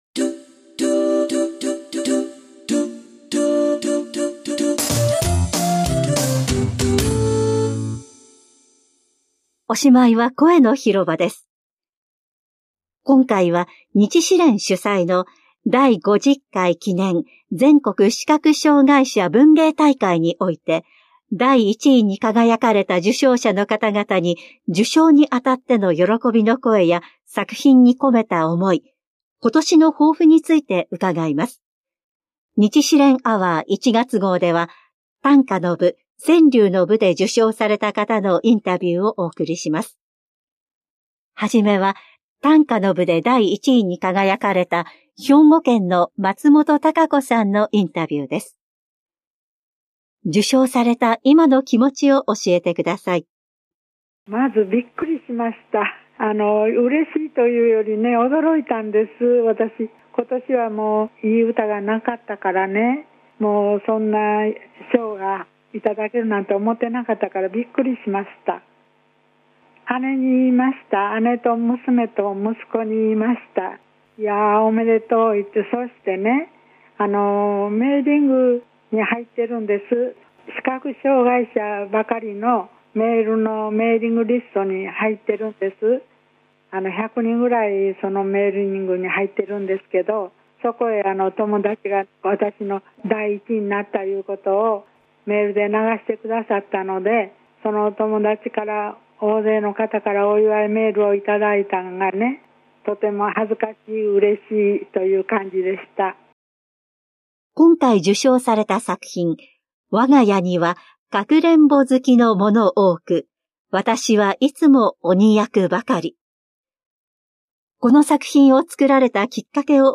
Tweet 聞く (8分1秒) 今回の「声のひろば」では、日視連主催の第50回記念 全国視覚障害者文芸大会において、短歌の部、川柳の部で第１位に輝かれた受賞者の方々に受賞にあたっての喜びの声や作品に込めた思い、今年の抱負について伺います。